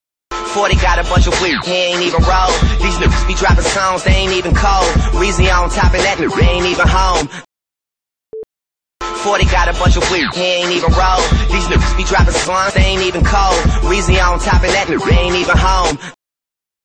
Where like it sounds like a DJ scratch?